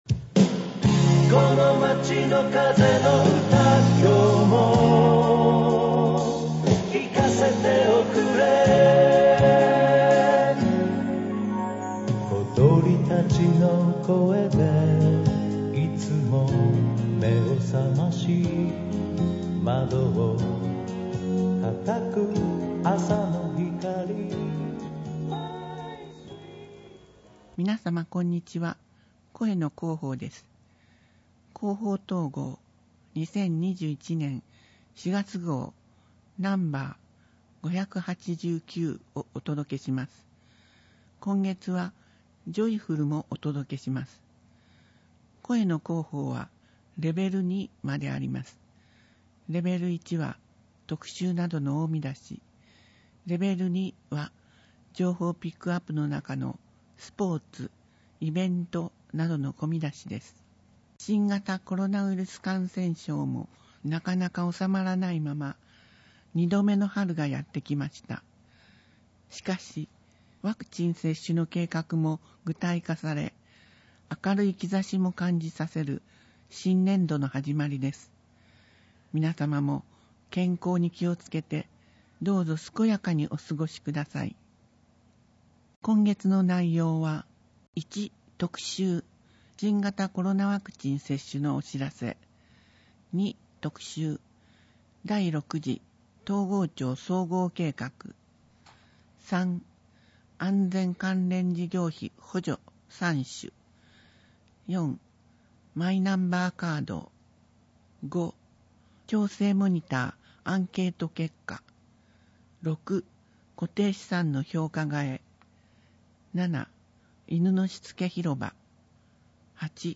広報とうごう音訳版（2021年4月号）